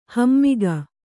♪ hammiga